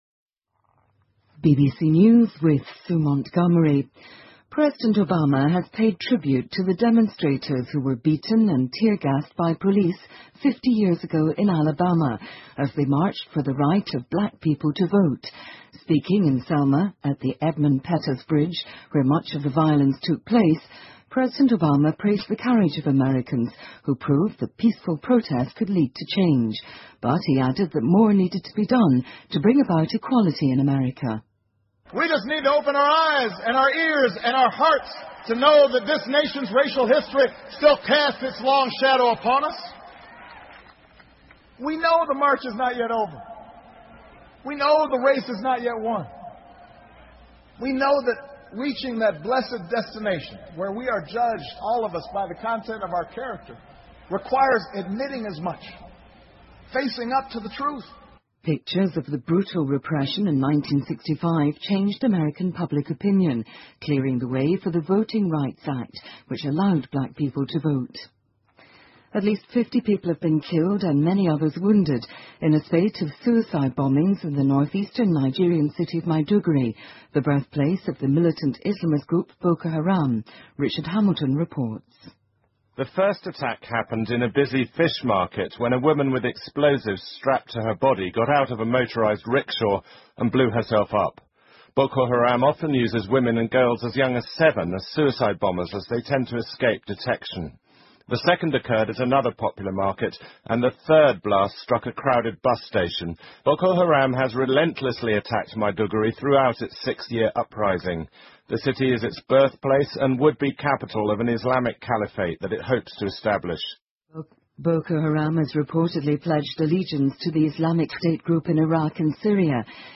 英国新闻听力 奥巴马向争取黑人选举权的游行者致敬 听力文件下载—在线英语听力室